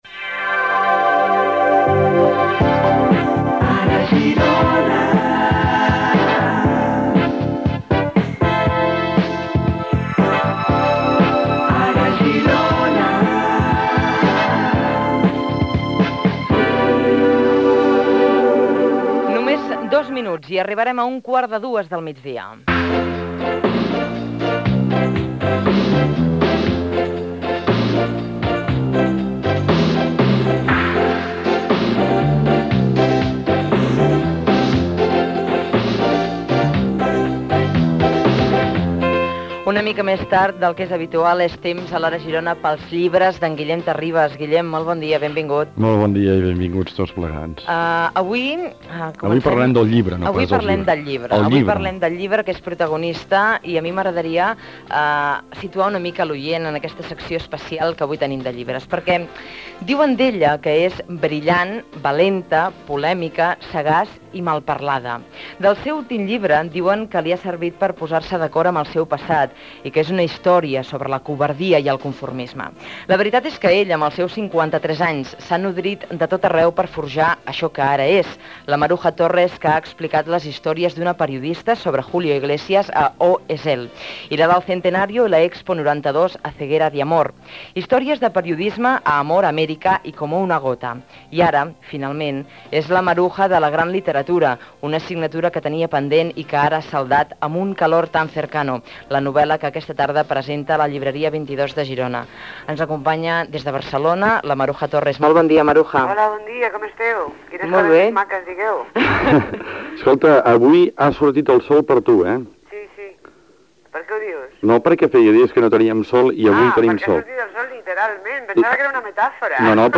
Indicatiu del programa, hora, secció "Els llibres" amb una entrevista a la periodista i escriptora Maruja Torres.
Info-entreteniment